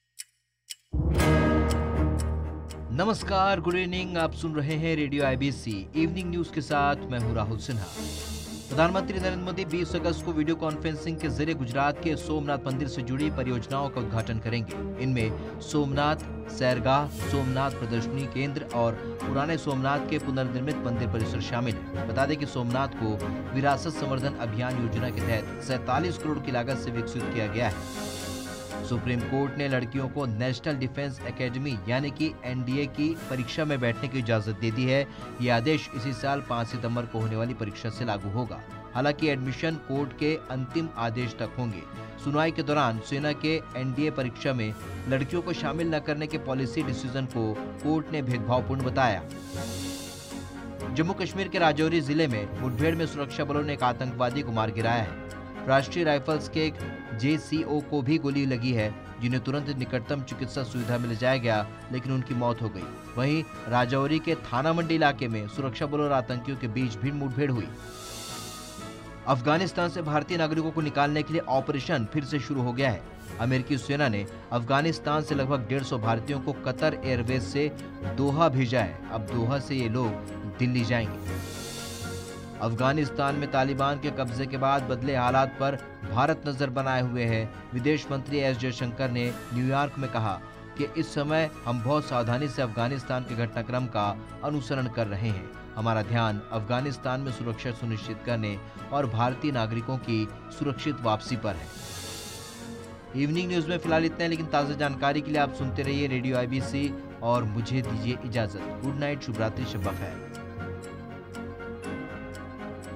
Evening News